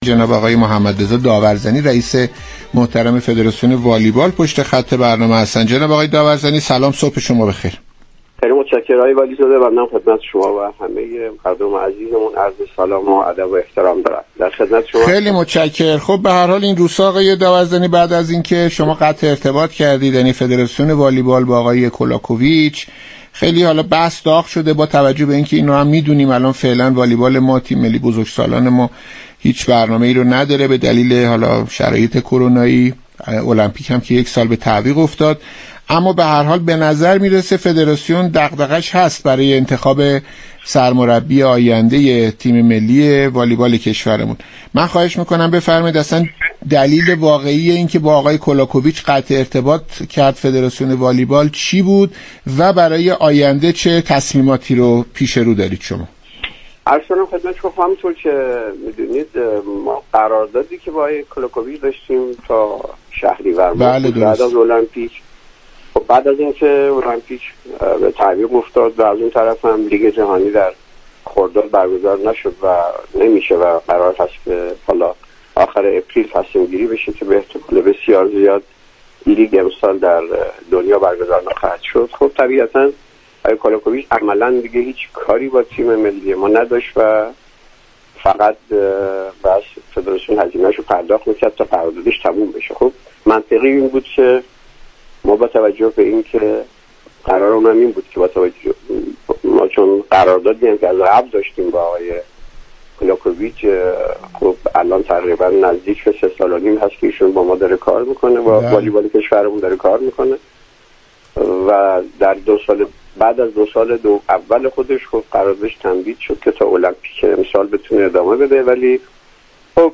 محمدرضا داورزنی، رئیس فدراسیون والیبال درباره فسخ قرارداد ایگور كولاكوویچ، سرمربی تیم ملی والیبال و انتخاب سرمربی جدید با برنامه «صبح و ورزش» رادیو ورزش گفتگو كرد.